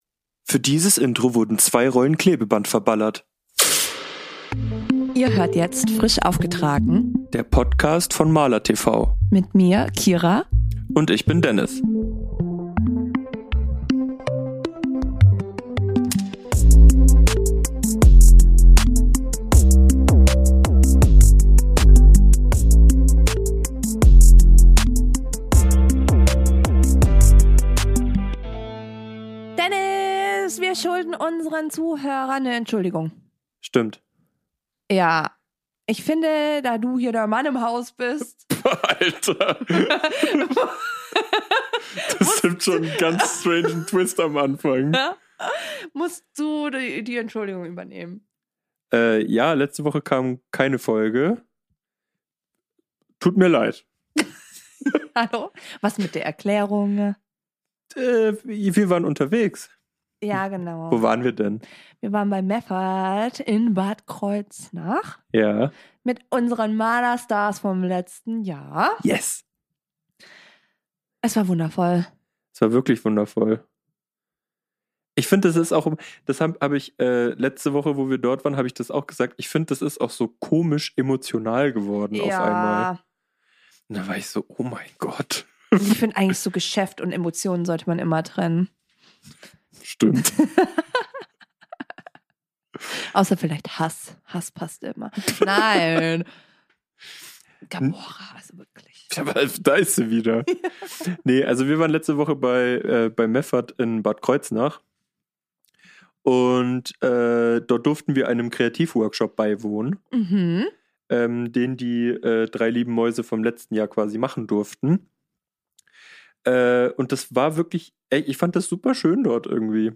Wir waren bei Meffert in Bad Kreuznach. Dort haben wir mit unseren MALERStars 2024 gesprochen..